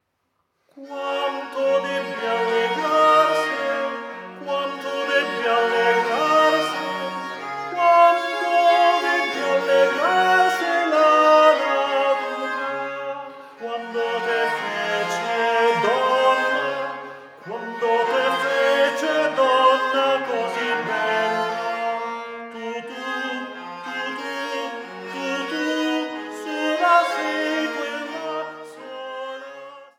Kleindiskantgeige
Diskantgeige
Tenorgeige
Bassgeigen
Sopran